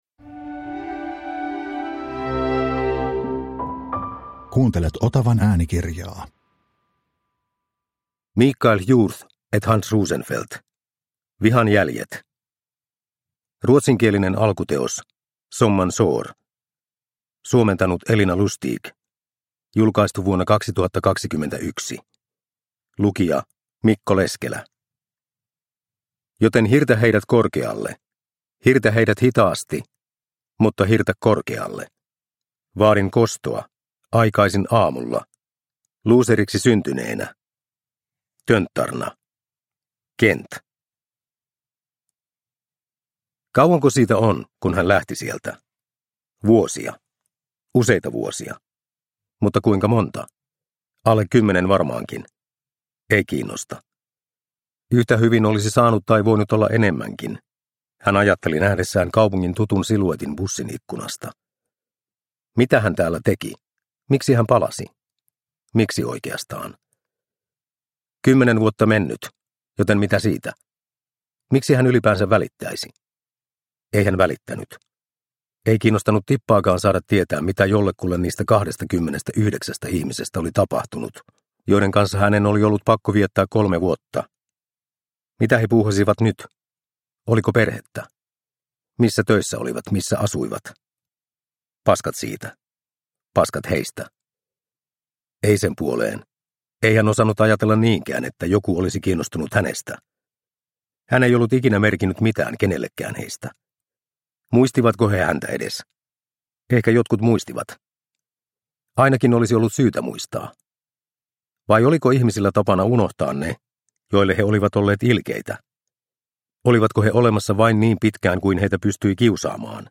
Vihan jäljet – Ljudbok – Laddas ner